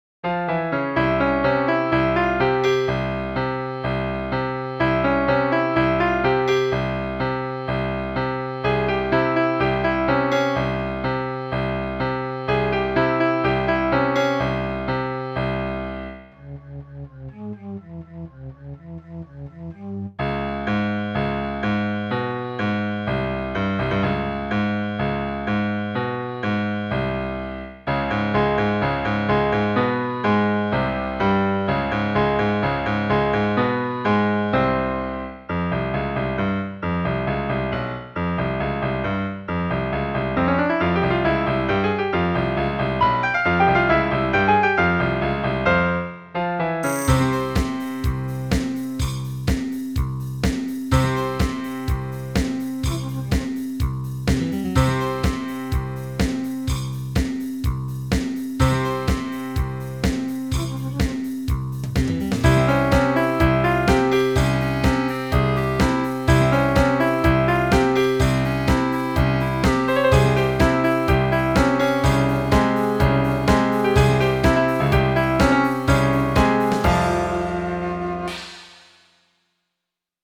Based on the byzantine scale.
MIDDLE EASTERN MUSIC ; ROCK MUSIC